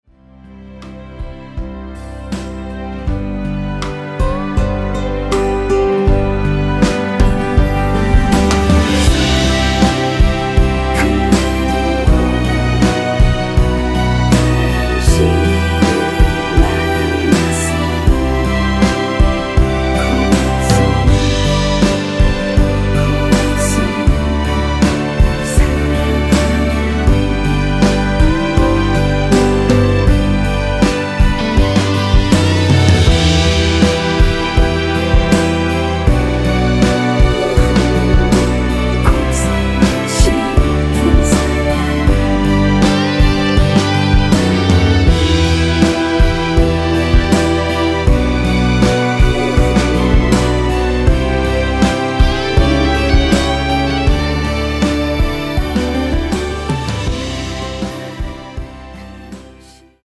원키에서(+1)올린 코러스 포함된 MR입니다.
앞부분30초, 뒷부분30초씩 편집해서 올려 드리고 있습니다.
중간에 음이 끈어지고 다시 나오는 이유는